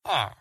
Villager Hmm
Play Villager Hmm Sound Button For Your Meme Soundboard!